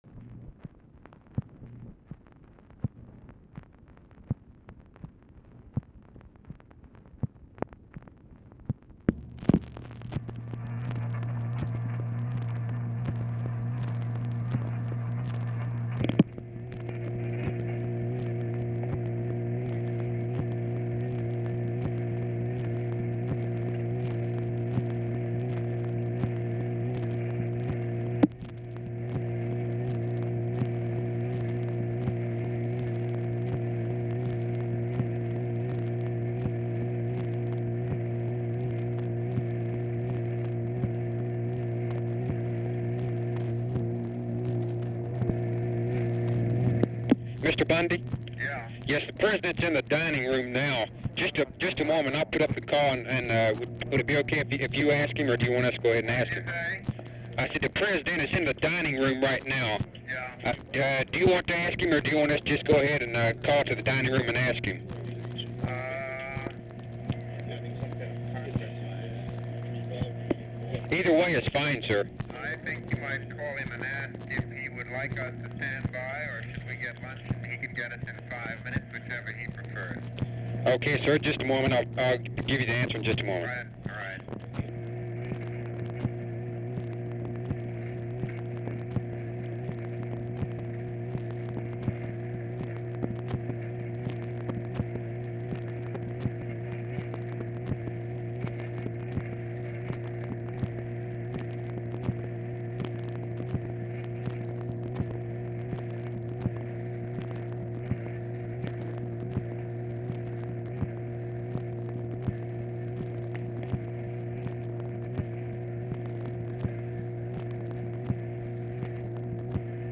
Telephone conversation
BUNDY IS IN DOMINICAN REPUBLIC AND IS DIFFICULT TO HEAR
Format Dictation belt